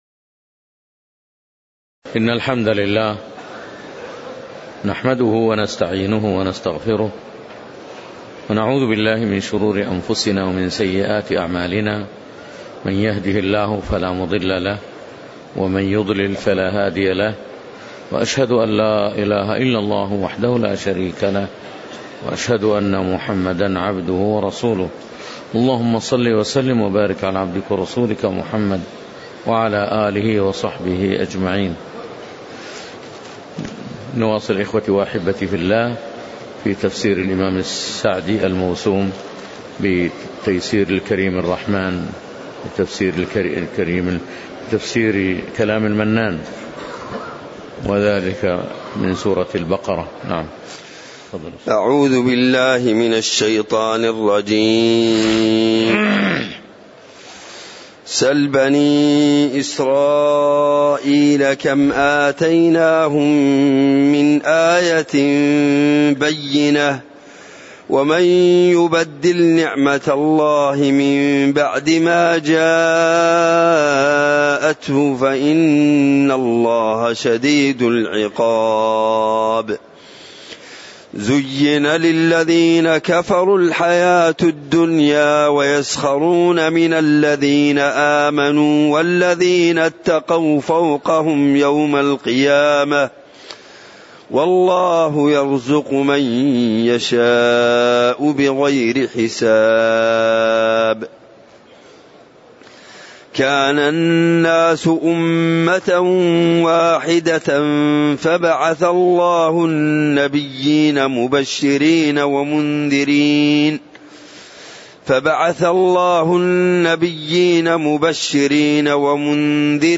تاريخ النشر ٢٥ ذو الحجة ١٤٣٨ هـ المكان: المسجد النبوي الشيخ